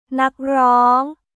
ナック・ローン